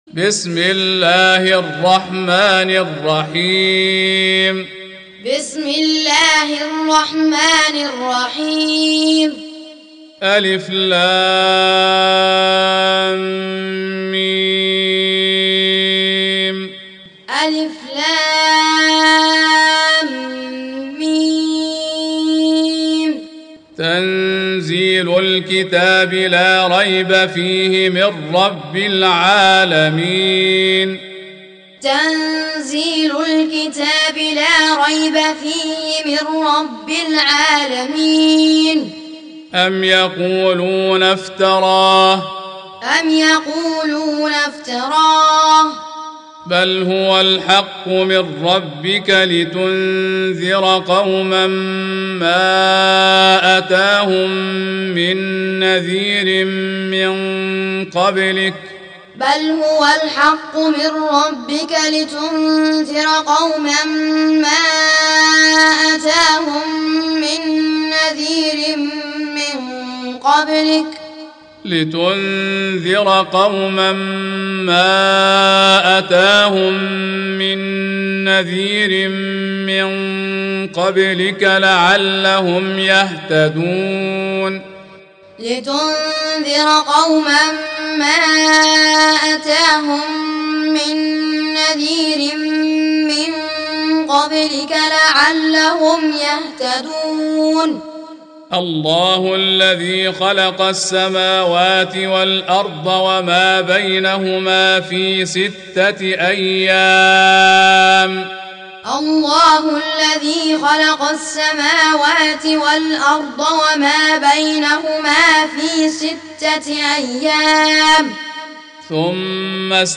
32. Surah As�Sajdah سورة السجدة Audio Quran Taaleem Tutorial Recitation Teaching Qur'an One to One
Surah Sequence تتابع السورة Download Surah حمّل السورة Reciting Muallamah Tutorial Audio for 32.